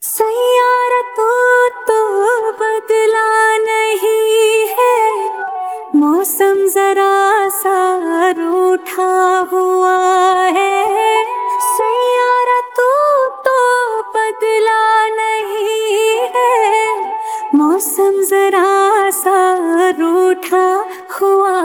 CategoryHindi Ringtones